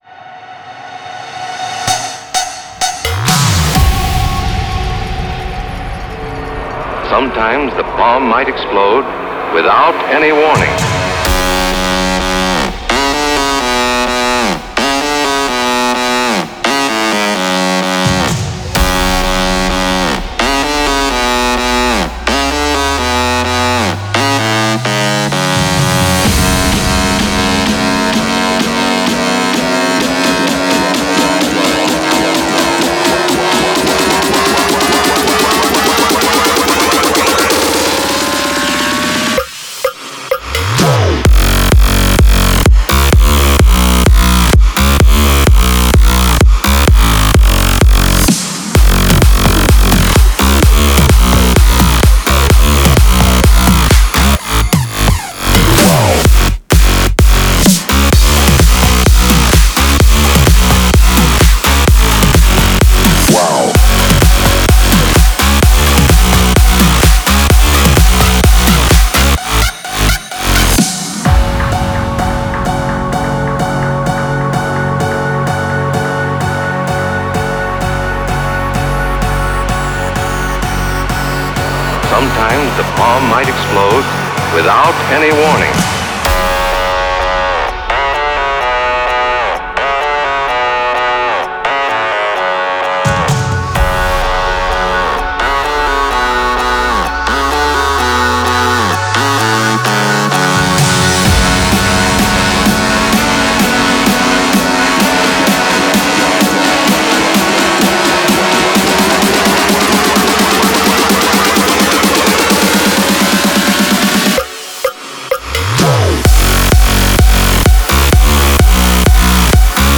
Жанр:Зарубежные новинки / Клубные новинки